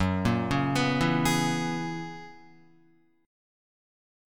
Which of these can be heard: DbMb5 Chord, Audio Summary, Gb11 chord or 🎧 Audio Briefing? Gb11 chord